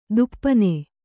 ♪ duppane